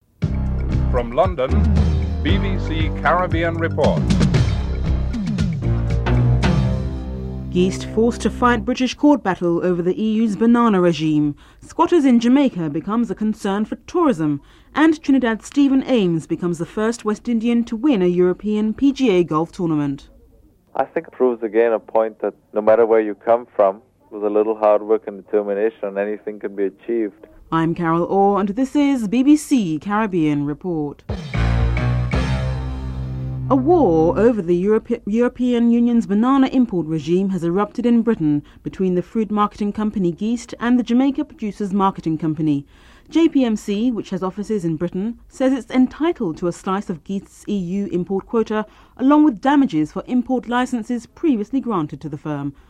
10. Wrap up & theme music (14:46-15:07)